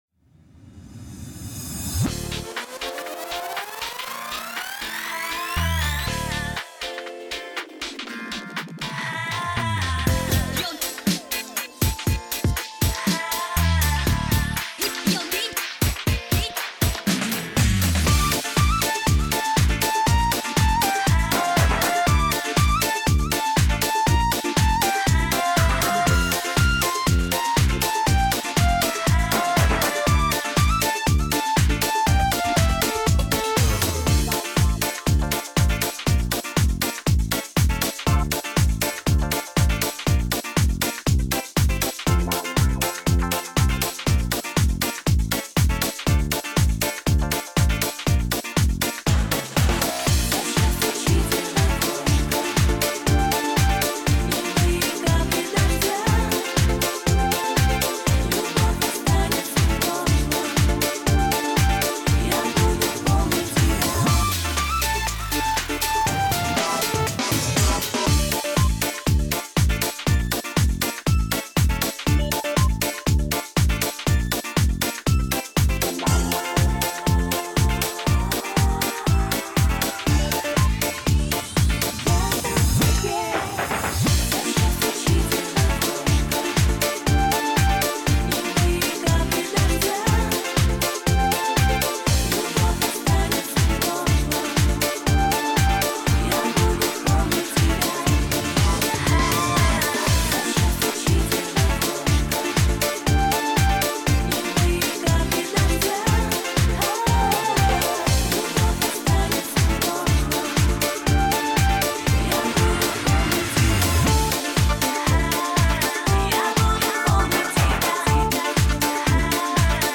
минусовка версия 222186